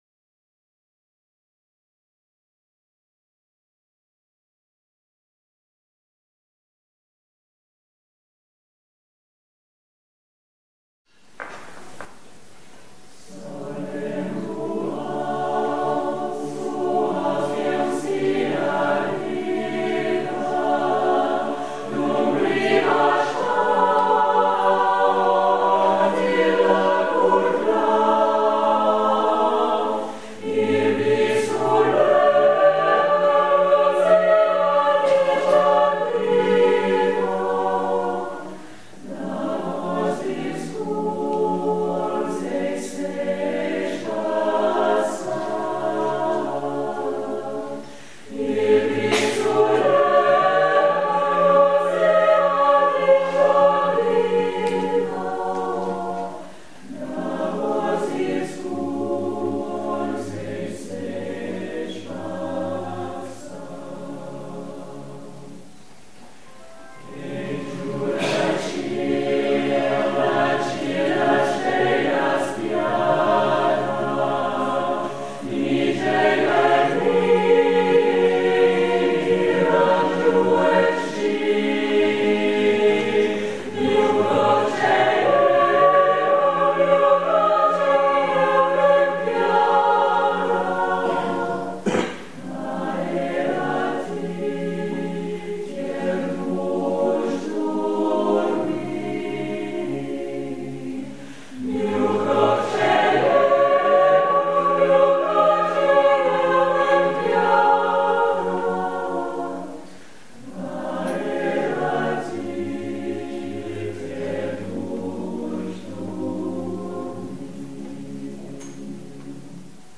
R�p�tition de la pi�ce musicale N� 664